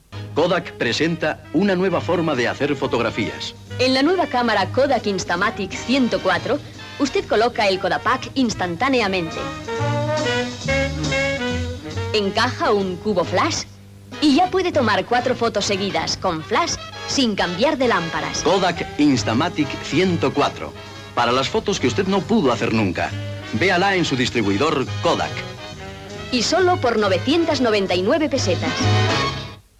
Publicitat de la màquina de retratar Kodak Instamatic 104 Gènere radiofònic Publicitat